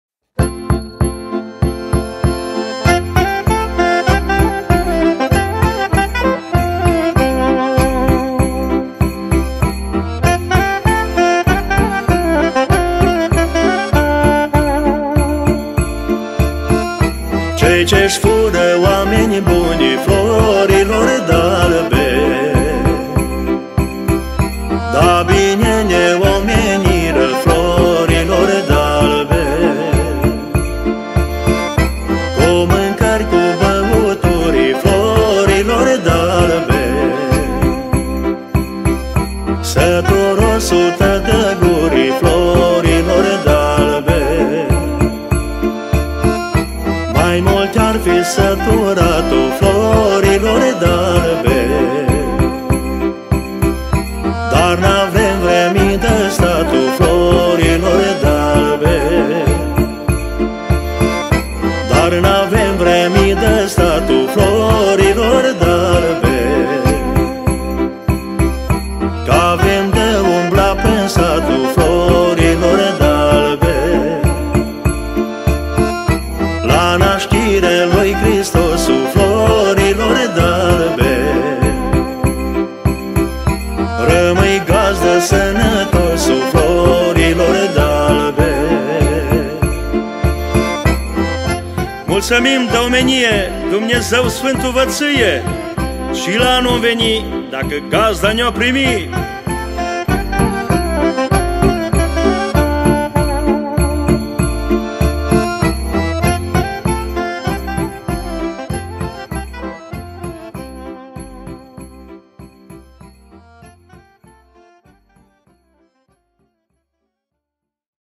Categoria: Colinde Craciun
colind